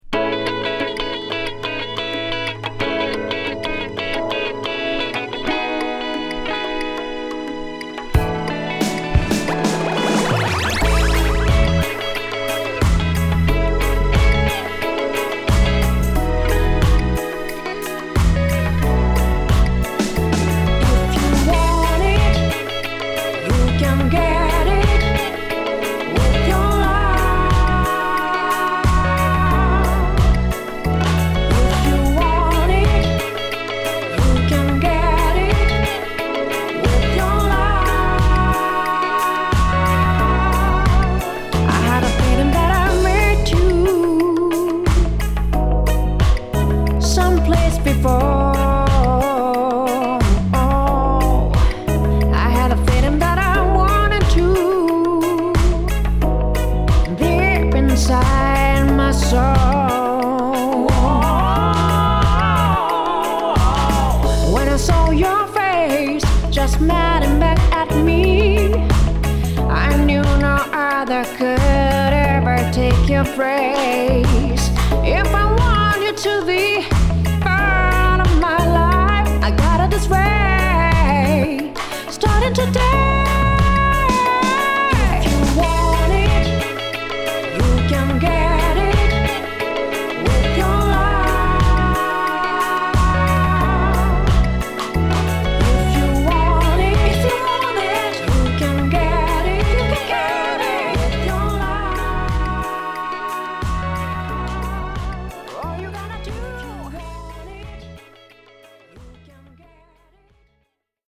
(Vocal)